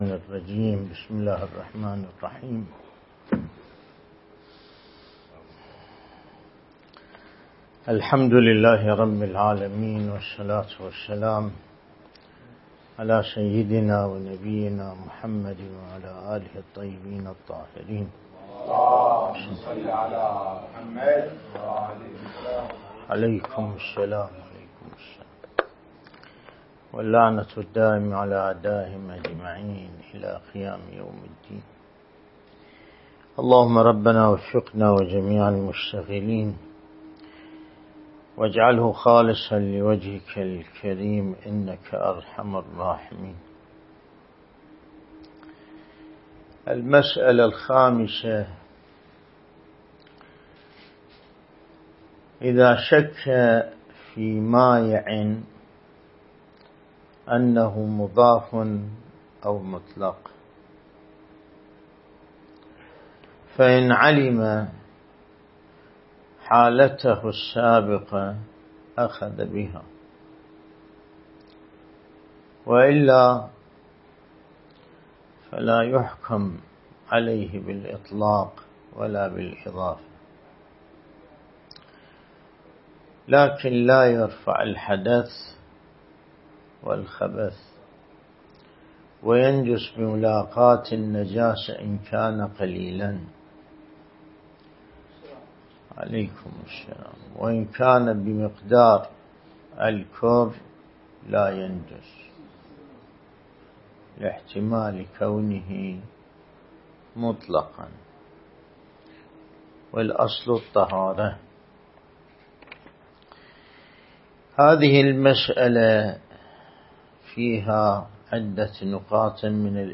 الدرس الاستدلالي شرح بحث الطهارة من كتاب العروة الوثقى